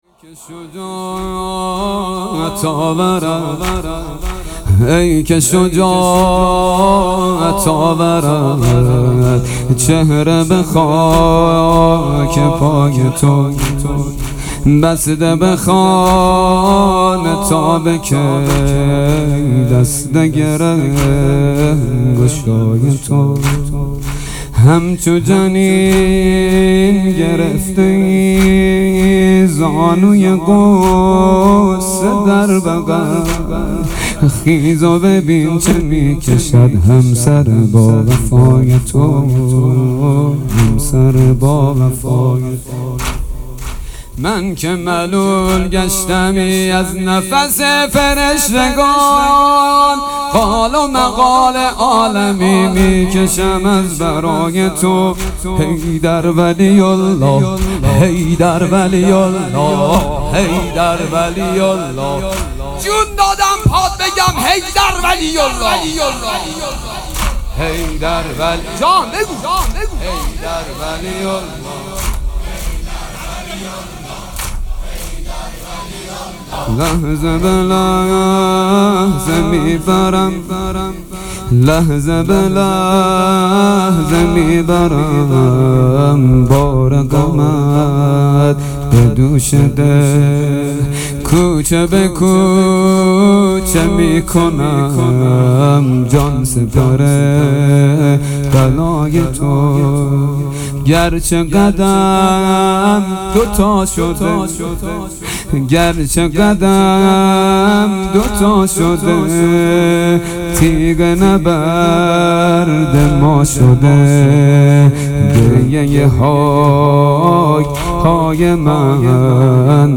حسینیه بیت النبی